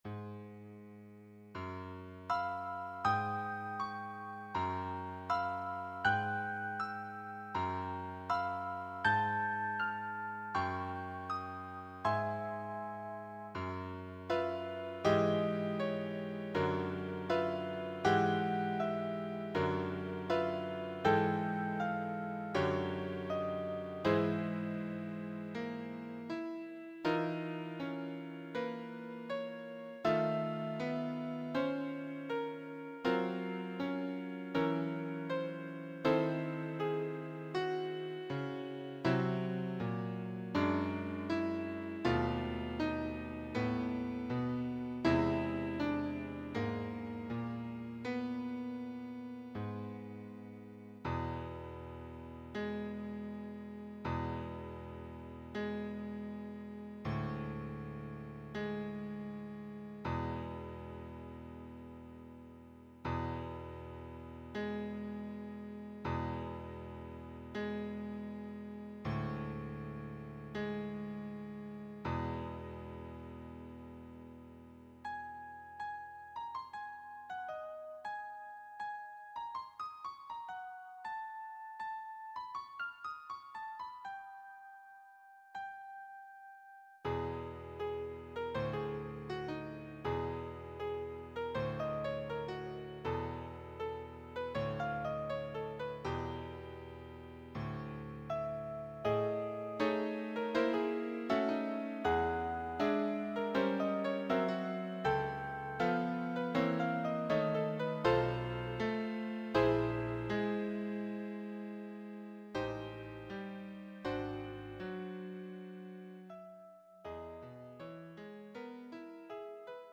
Solo piano piece written for the wedding of friends and awarded first prize in the teachers’ class of the EPTA composing competition, 2007